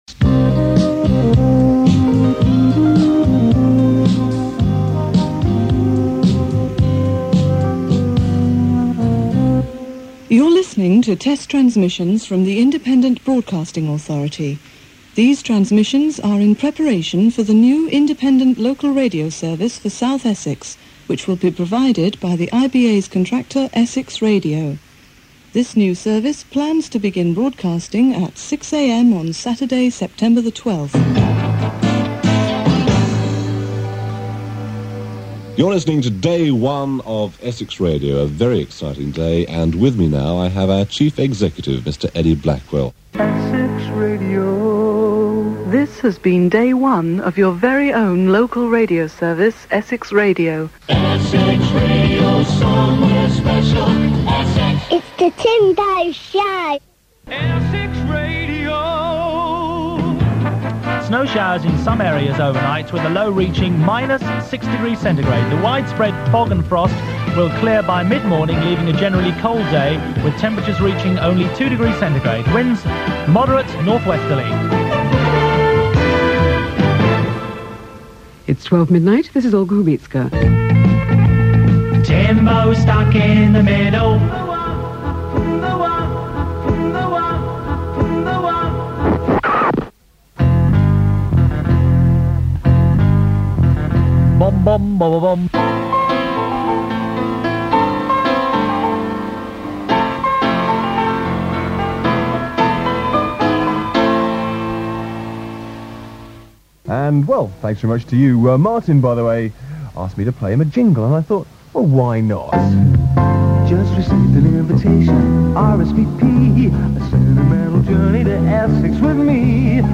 1981 Various General Air Checks part 1